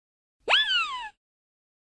Звуки удивления, мультфильмов
Эффект удивления